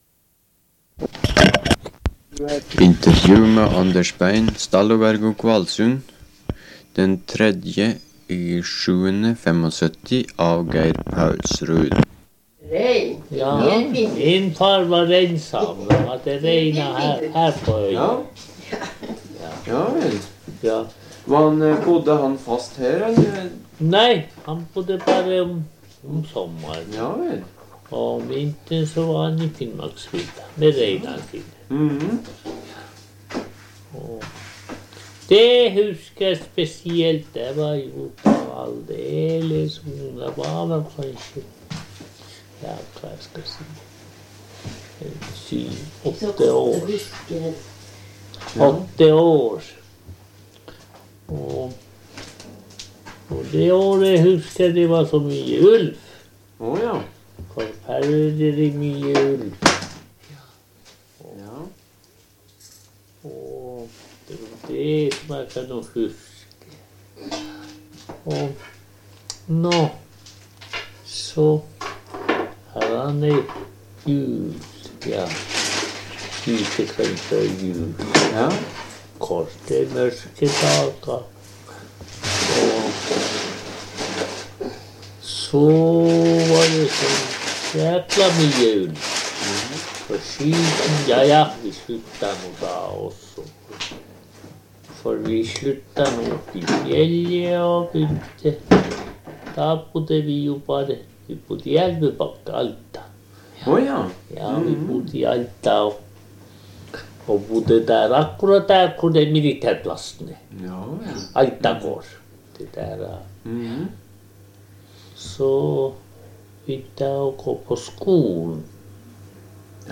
Sted: Kvalsund, Stallogargo